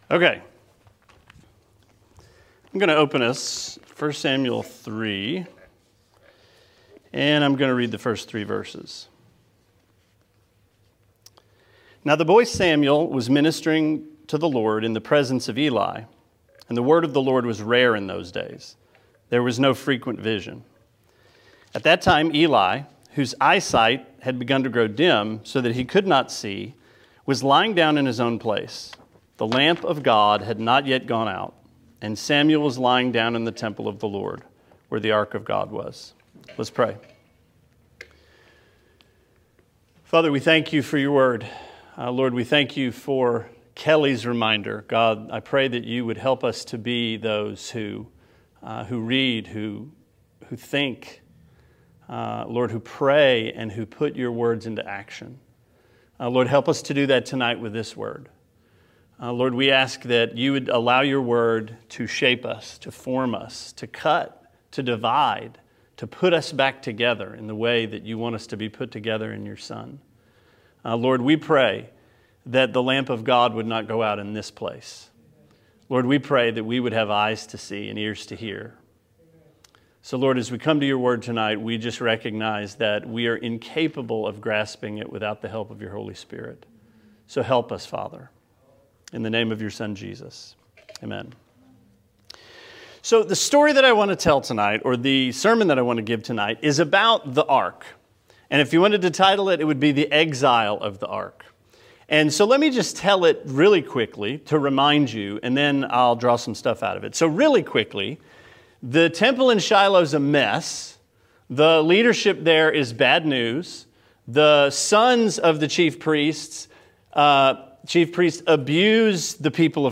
Sermon 6/12: 1 Samuel 3-7: The Ark in Exile